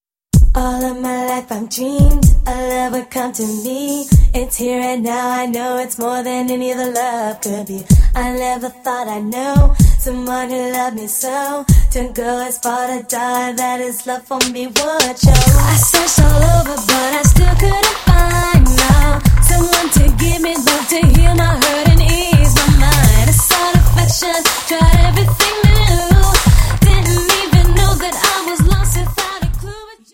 Ist das Gospel, ist das Soul, ist das R'n'B?
• Sachgebiet: Urban